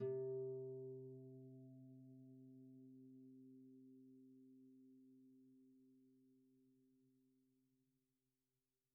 KSHarp_C3_mf.wav